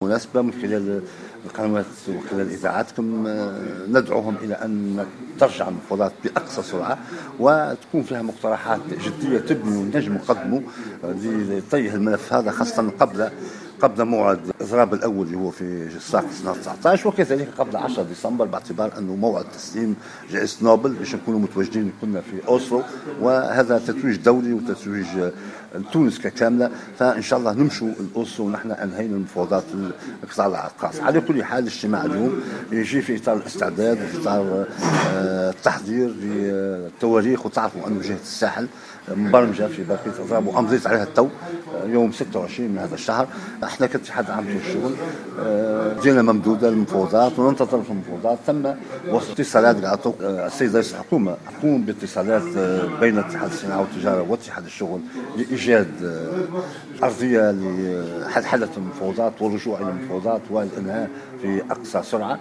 وقال في تصريح لـ "الجوهرة اف أم" على هامش اجتماع نقابي في مقر الاتحاد الجهوي للشغل بالمنستير، إنه اليوم تولى التوقيع على وثيقة موعد الاضراب الجهوي بالساحل المبرمج، مذكرا أن الاضرابات الجهوية ستنطلق بدءا من صفاقس يوم 19 نوفمبر الحالي.